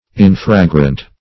infragrant - definition of infragrant - synonyms, pronunciation, spelling from Free Dictionary
Infragrant \In*fra"grant\, a.